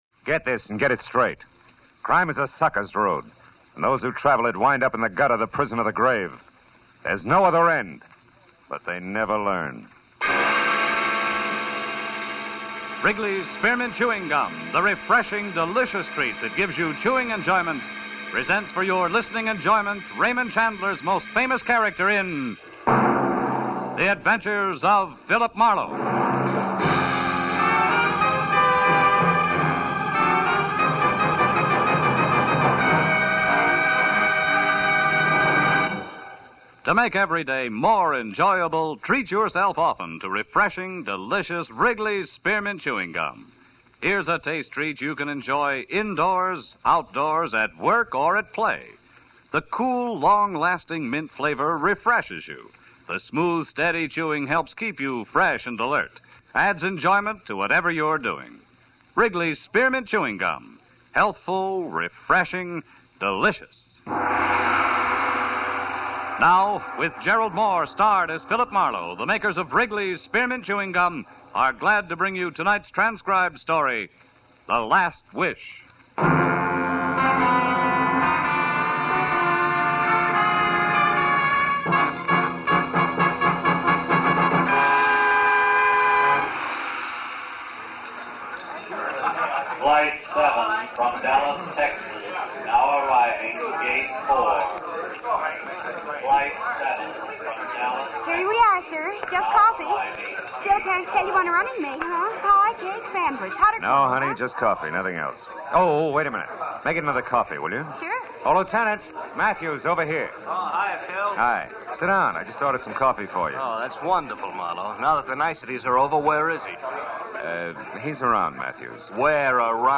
In 1948, the series moved to CBS, where it was called The Adventure of Philip Marlowe, with Gerald Mohr playing Marlowe.
The program first aired 17 June 1947 on NBC radio under the title The New Adventures of Philip Marlowe, with Van Heflin playing Marlowe.